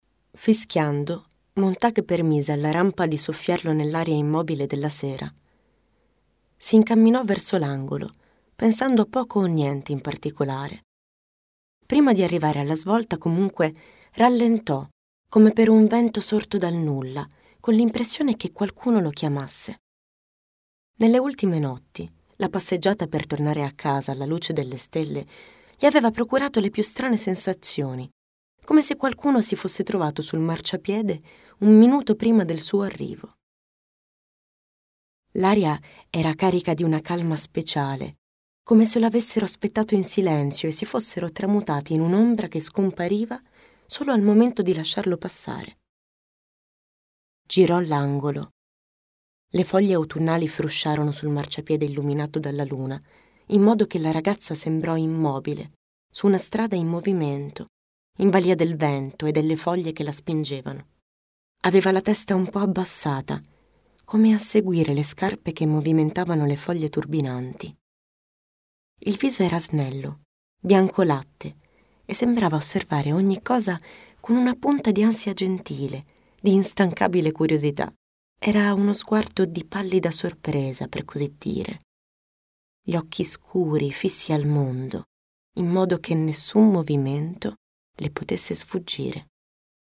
Attrice e speaker italiana. Una voce calda e avvolgente.
Sprechprobe: Sonstiges (Muttersprache):
A deep and enclosing voice for your needs.